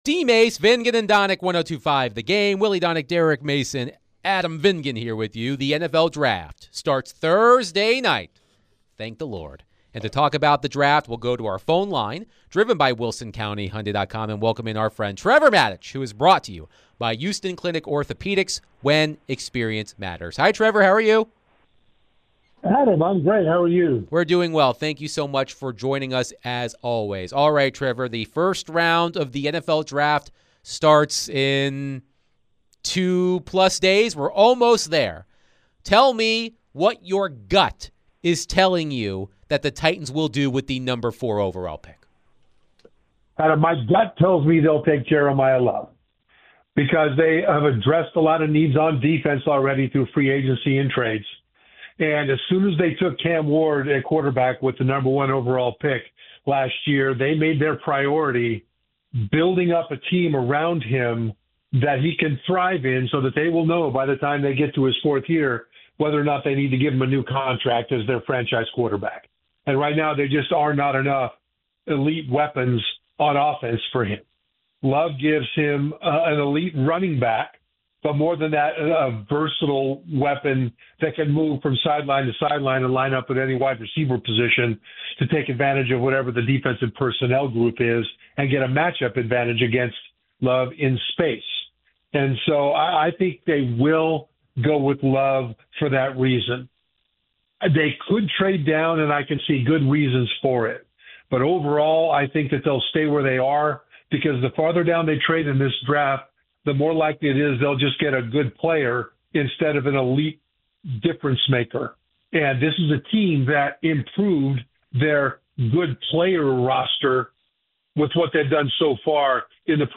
ESPN NFL Analyst Trevor Matich joins DVD to discuss all things NFL Draft, Titans, and more